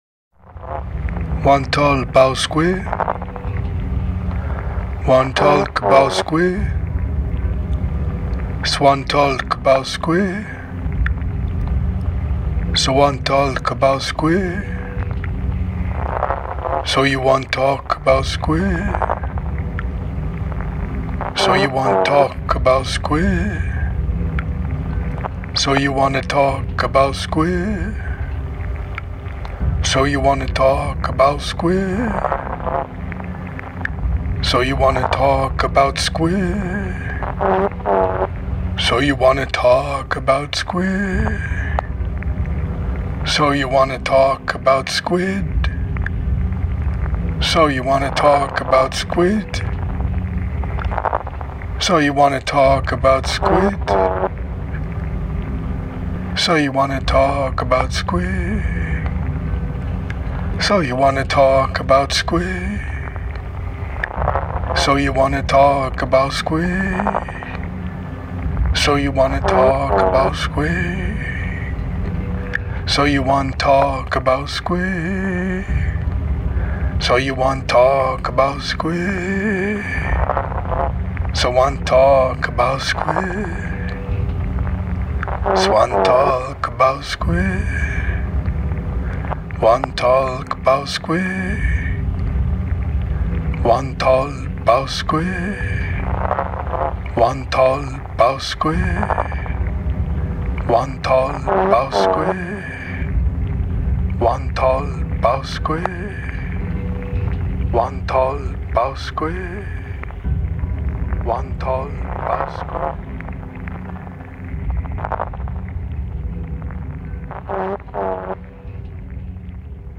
Somehow, through instinct or intuition, I sensed that my only hope was to write down what I was hearing in my head, and as I did so, indeed, a squidlike form began to emerge on the page, a form that then became a score for a bit of fungal audiophony: